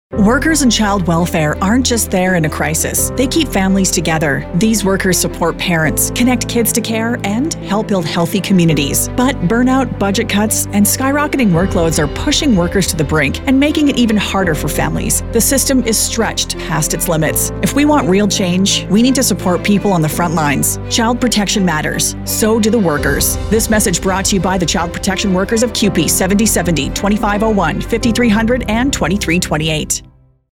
Radio Ad - CUPE 2328
Our radio ad is now on the air!